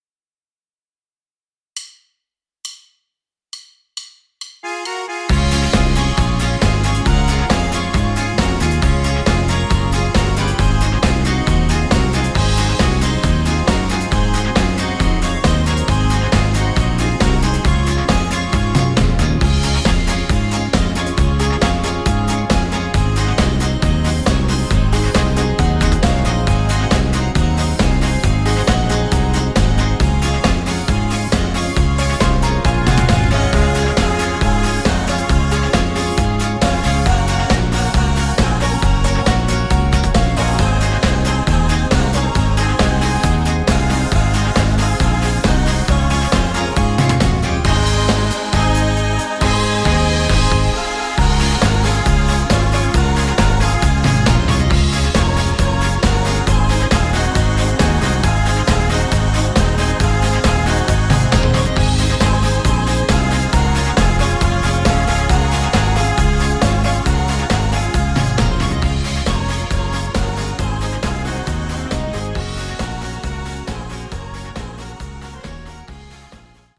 Karaoke, Instrumental